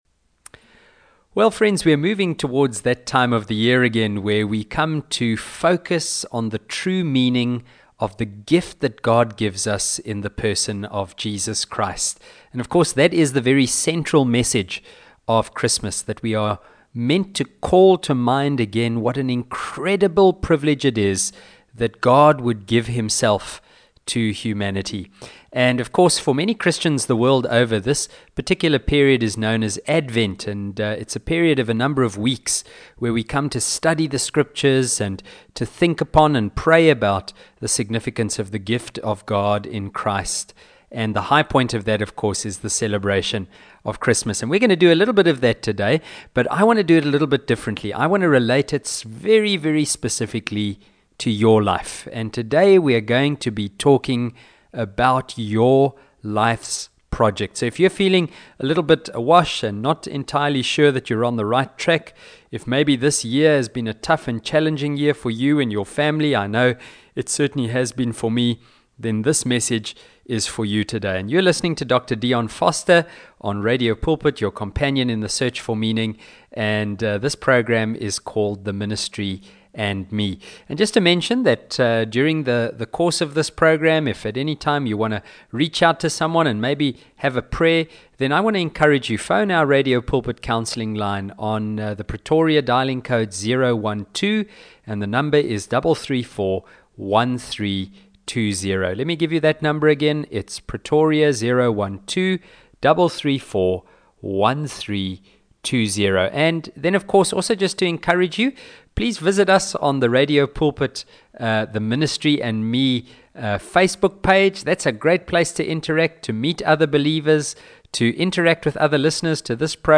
In this message, which was broadcast on Radio Pulpit this week, I consider the topic of your 'life's project' and some encouragements for finding peace and sufficiency as we approach Christmas.